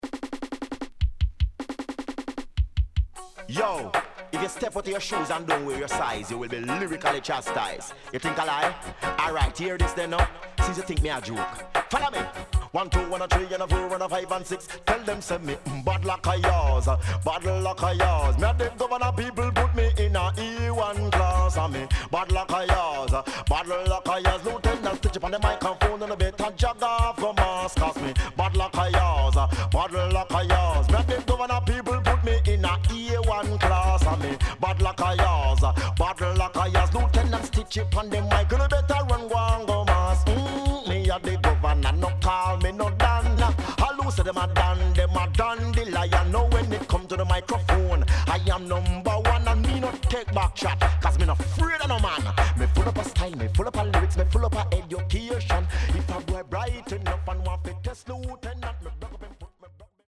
Nice Deejay